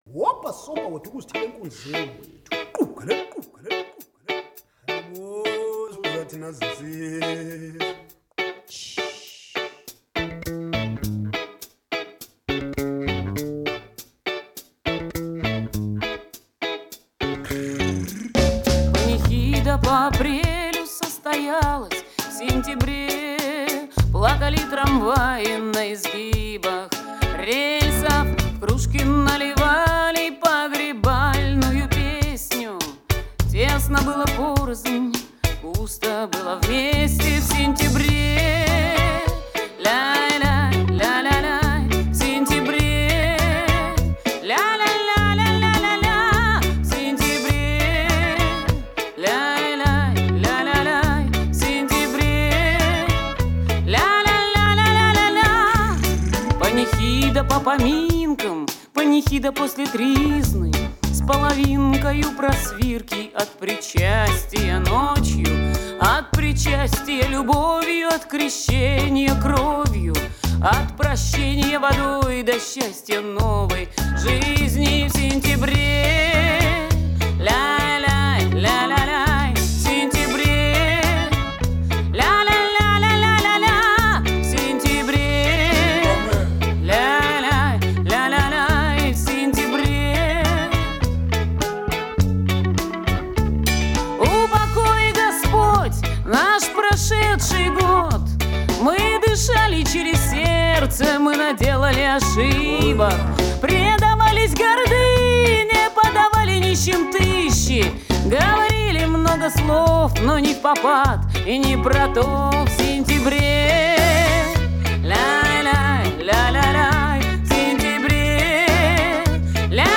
Студийный электрический альбом.
бас-гитара
клавиши
гитара
ударные, перкуссия